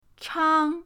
chang1.mp3